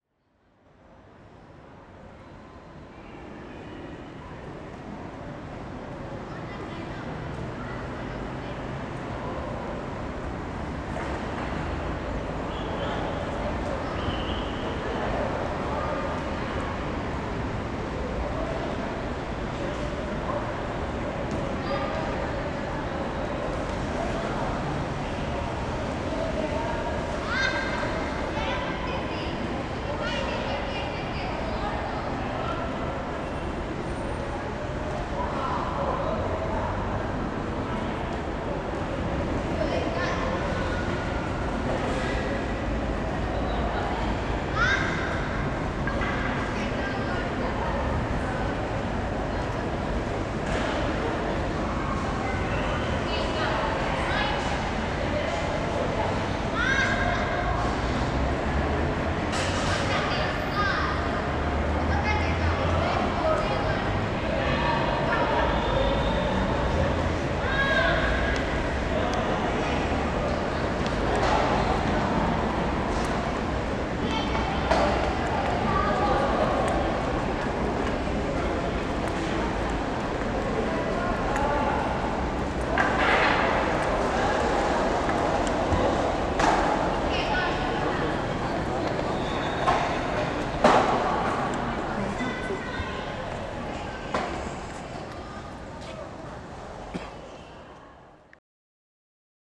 ambiente GYM.wav
HOLOFONIKA FOLEY MEDELLIN 2013 es una apuesta desde lo sonoro para ayudar y fortalecer la escena Audiovisual de Medellin , creando así un banco de sonidos propios de la ciudad, que pueden ser utilizados para el diseño sonoro, arte o simplemente para tener una memoria sonora de una ciudad.